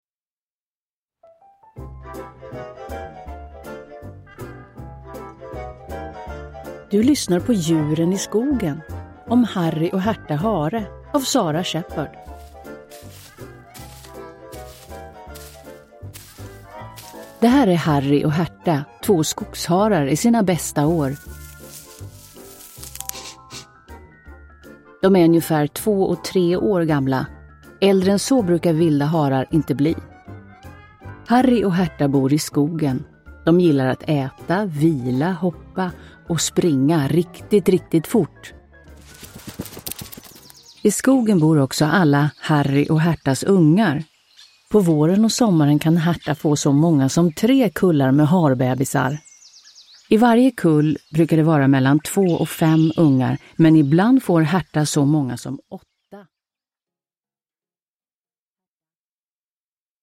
Harry och Härta Hare – Ljudbok – Laddas ner